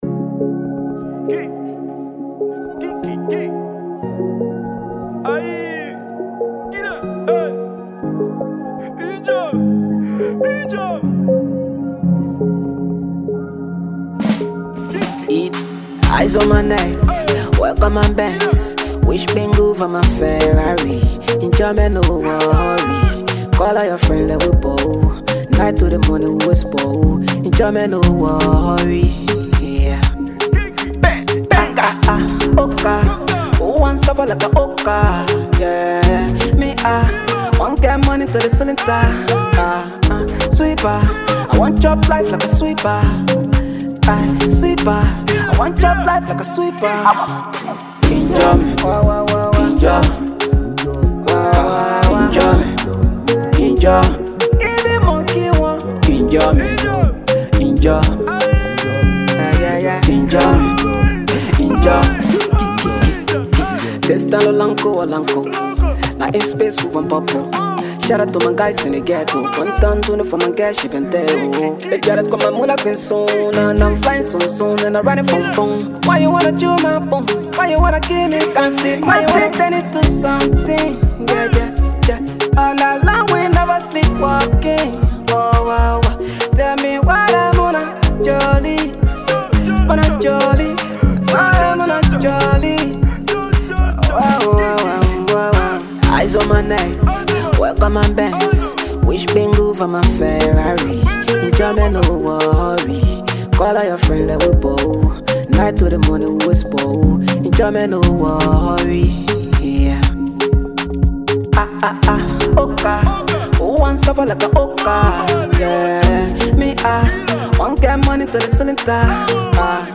Afro-Pop